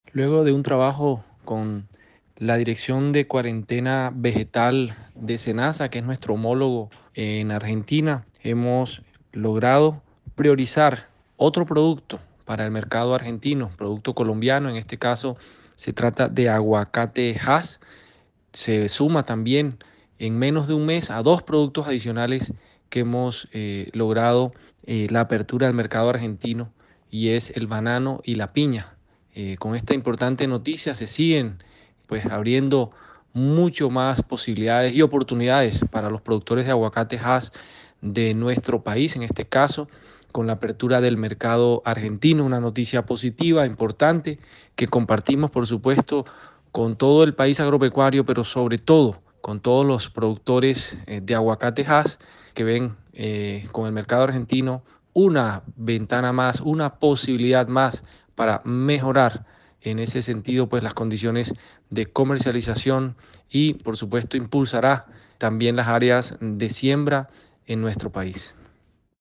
• Declaraciones del gerente general del ICA: